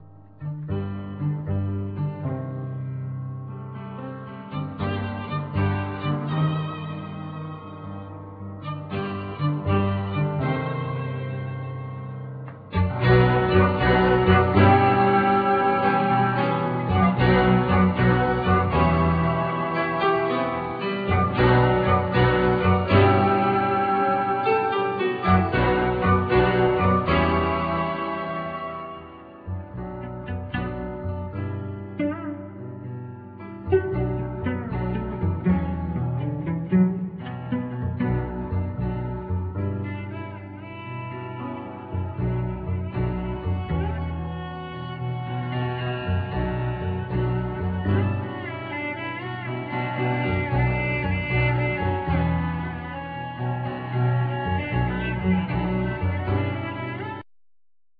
Vocals
Flute,Sax
Clarinet
Basoon
Piano,Flute
Violin,Trombone
Viola
Cello
Double bass